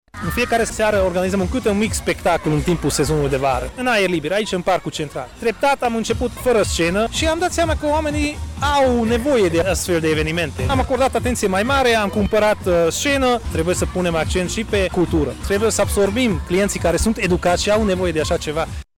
Primarul, Fulop Laszlo Zsolt.